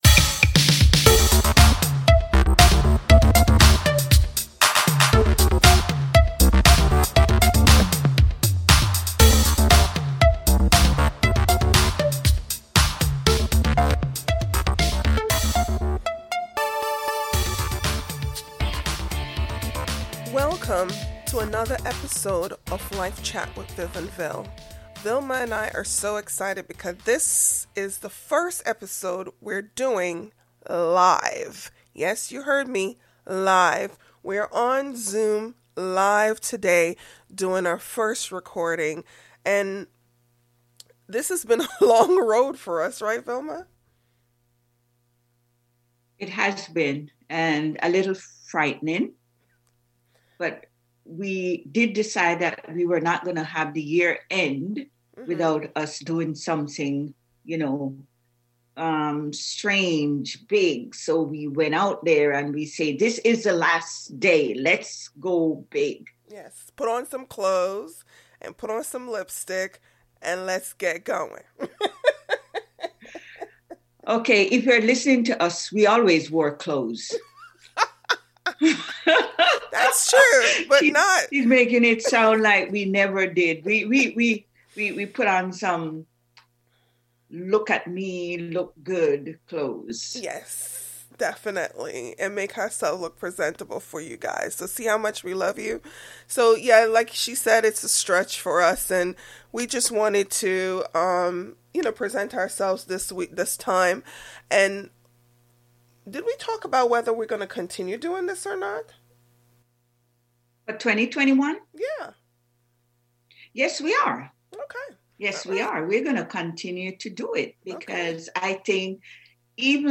Hosts speaking and declaring success for the New Year.